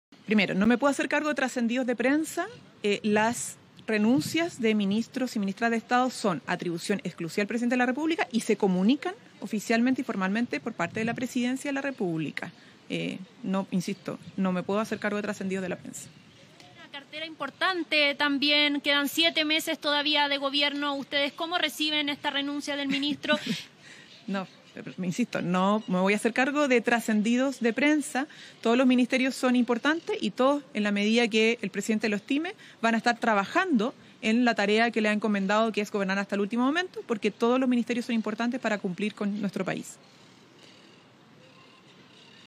La ministra vocera de Gobierno, Camila Vallejo, salió al paso de versiones que circulaban en distintos medios sobre posibles cambios en el gabinete y, en particular, eventuales renuncias de ministros.
Durante un punto de prensa, la secretaria de Estado descartó tajantemente la veracidad de estos trascendidos, subrayando que las renuncias de los integrantes del gabinete son una atribución exclusiva del Presidente de la República y que solo pueden comunicarse de manera oficial a través de la Presidencia.